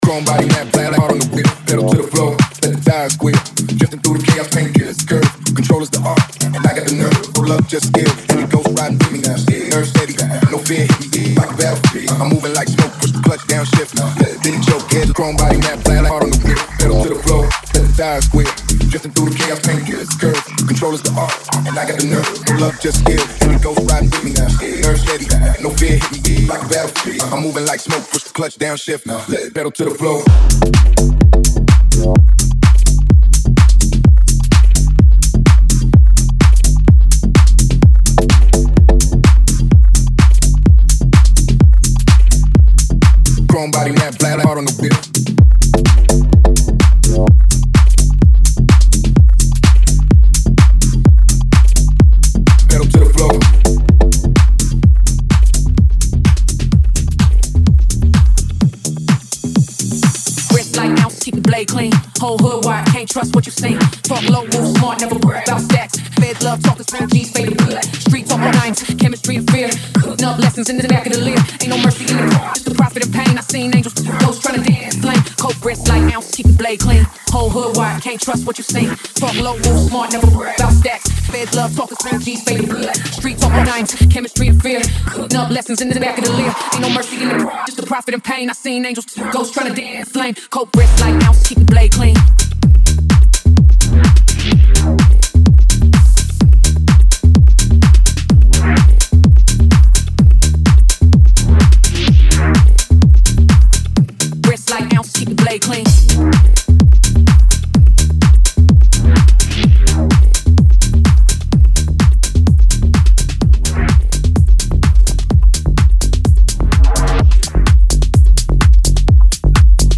Genre:Tech House
クリーンなグルーヴ、温かみのあるローエンド、そして緻密なパーカッションに焦点を当てて丁寧に制作されたコレクションです。
デモサウンドはコチラ↓